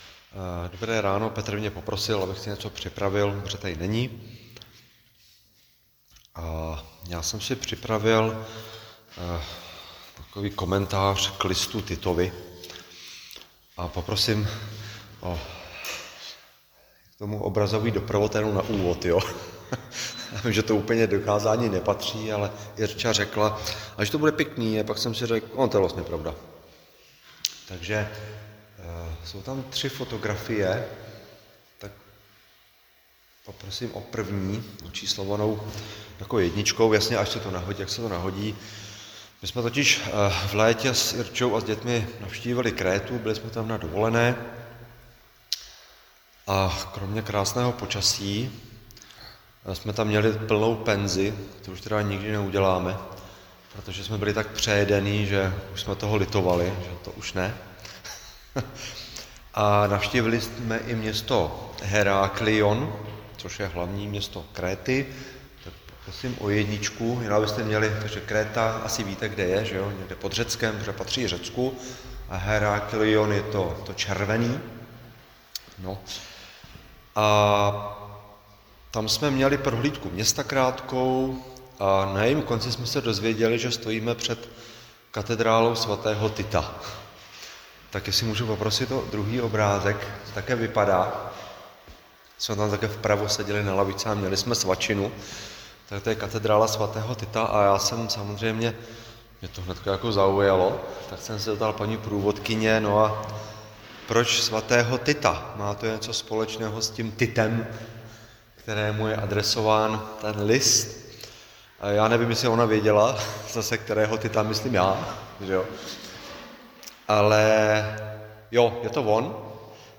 Křesťanské společenství Jičín - Kázání 28.9.2025